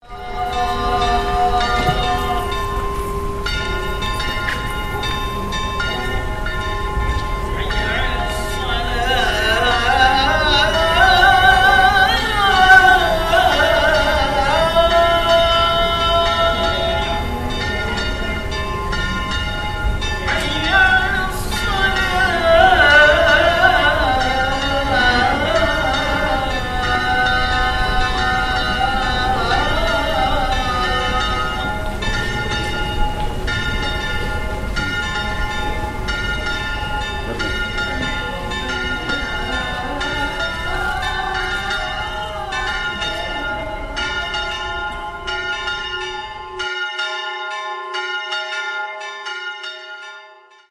Llamamiento a la oración musulmana y cristiana
campanas-muecin.mp3